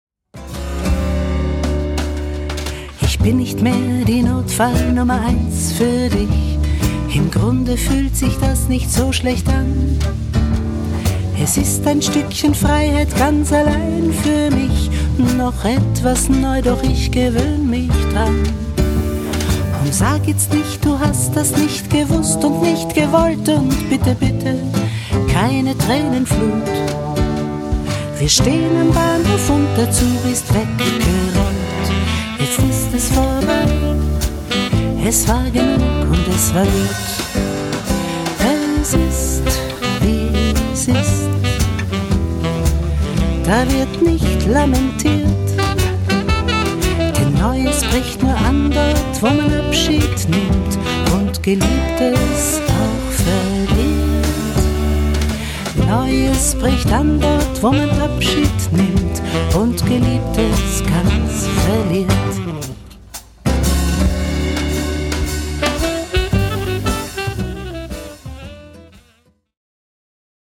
guitar, bass, ukulele, vocals, arrangements
viola da gamba, bass
akkordion, vocals